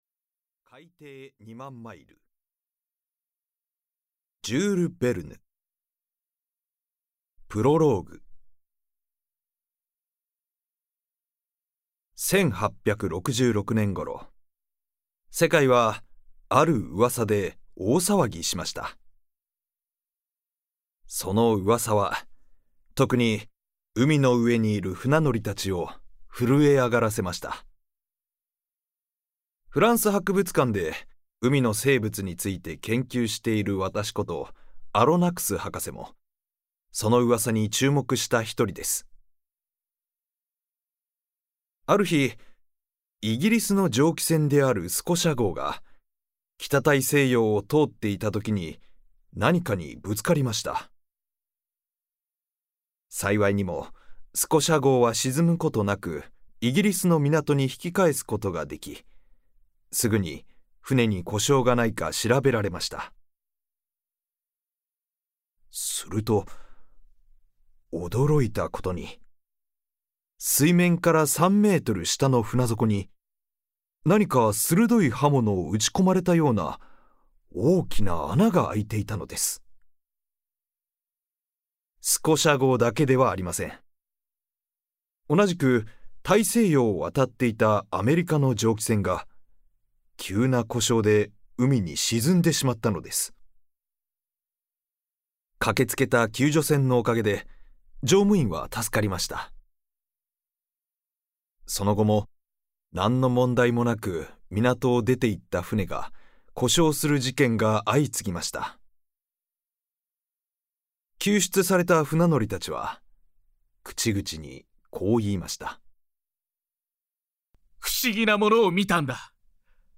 [オーディオブック] 海底二万マイル（こどものための聴く名作 18）